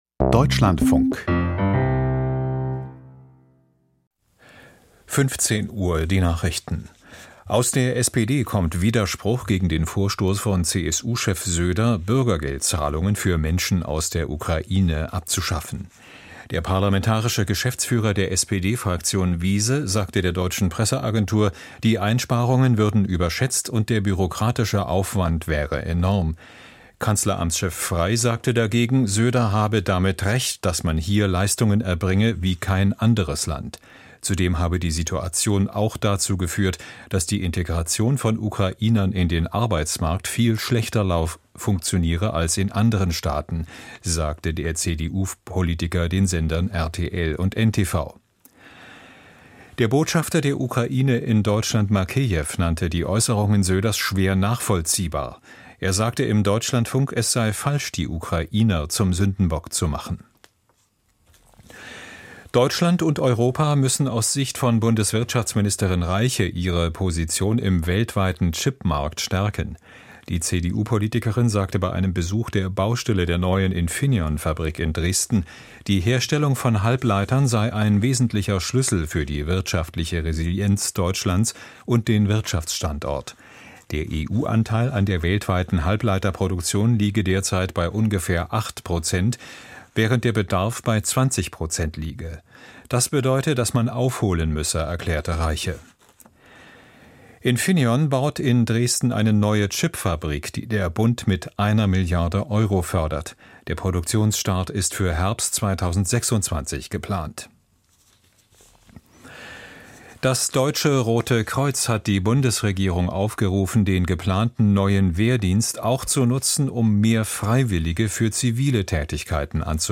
Die Nachrichten vom 04.08.2025, 15:00 Uhr